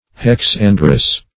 Search Result for " hex-androus" : The Collaborative International Dictionary of English v.0.48: Hexandrian \Hex*an"dri*an\, Hex-androus \Hex-an"drous\, a. [Cf. F. hexandre.]